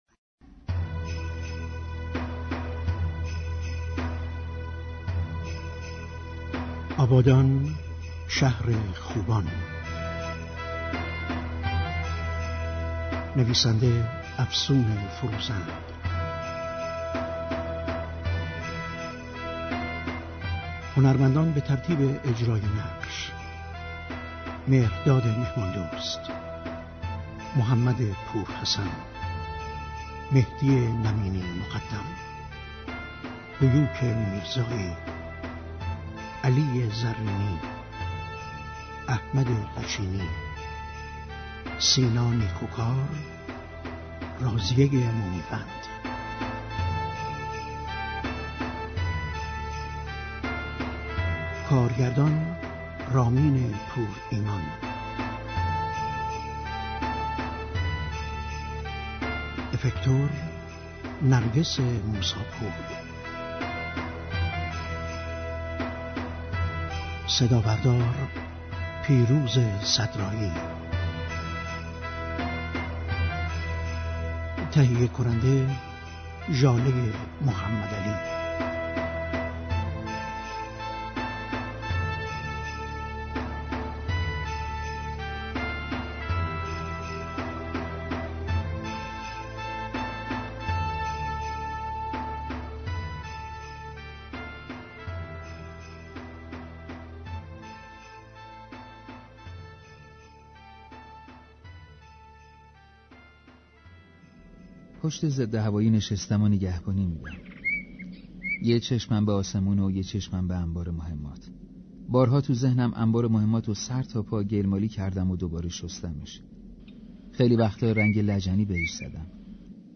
پنجم مهر ماه ، نمایش رادیویی